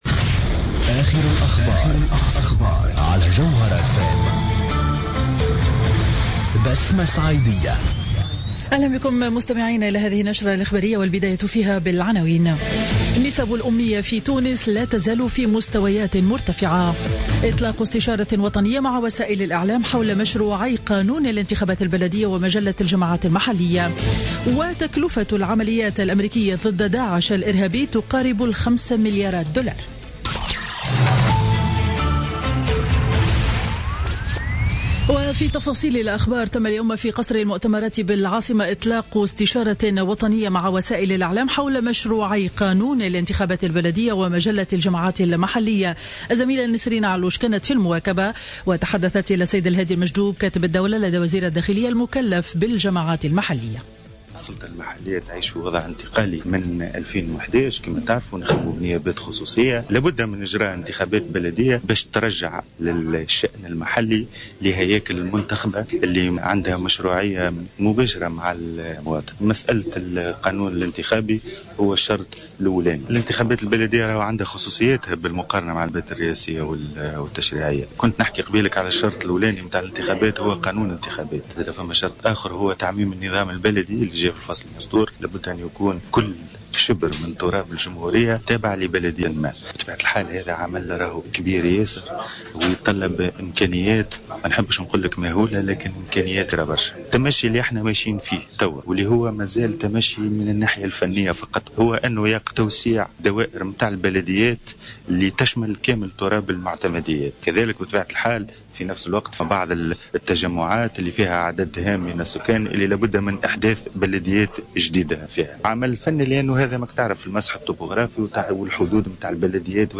نشرة أخبار منتصف النهار ليوم الجمعة 30 أكتوبر 2015